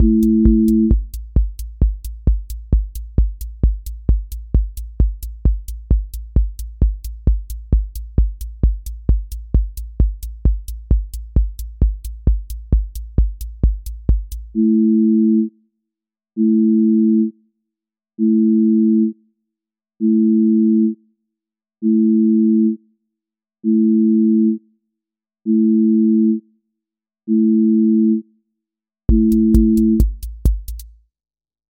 trance ascent with breakdown and drop
• voice_kick_808
• voice_hat_rimshot
• voice_sub_pulse
• fx_space_haze_light
• tone_brittle_edge